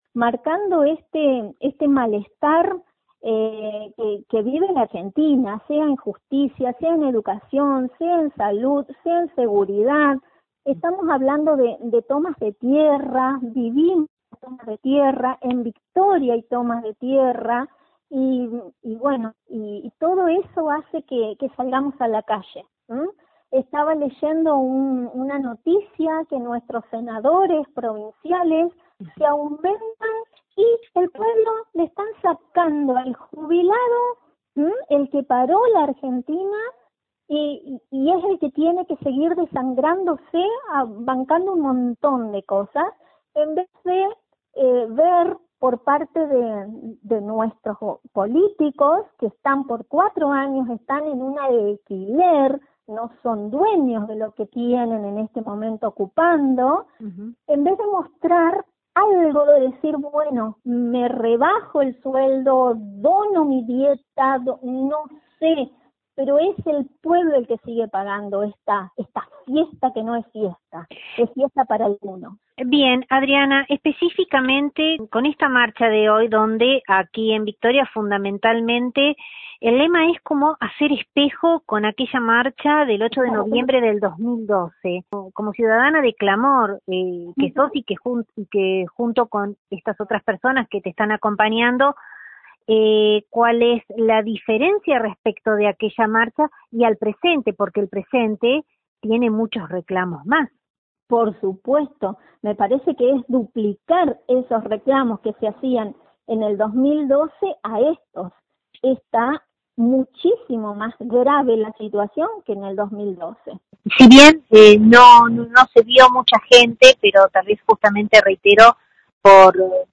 dialogamos con una de las participantes de la marcha